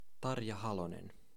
Tarja Kaarina Halonen (pronounced
[tɑrjɑ kɑːrinɑ hɑlonen]) (born 24 December 1943) is a former President of Finland.
Fi-Tarja_Halonen.ogg.mp3